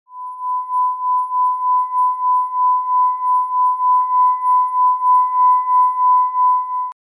powerful frequency sound wave